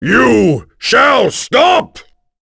Kaulah-Vox_Skill1.wav